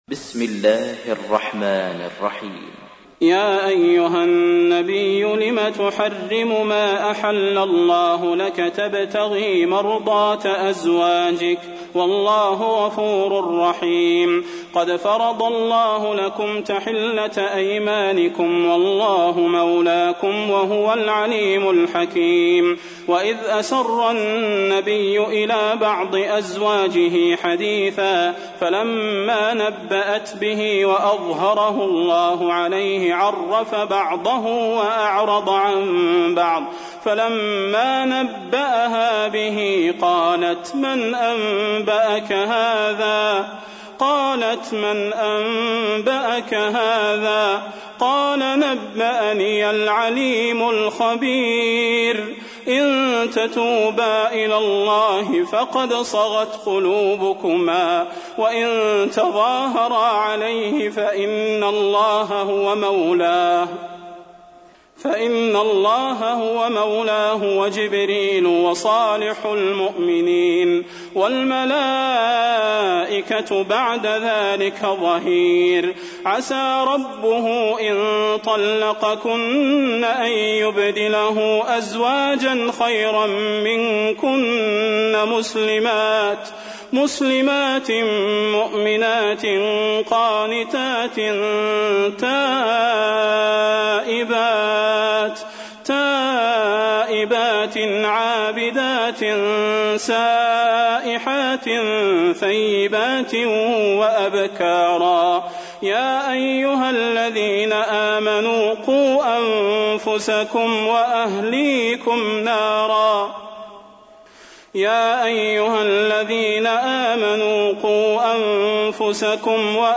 تحميل : 66. سورة التحريم / القارئ صلاح البدير / القرآن الكريم / موقع يا حسين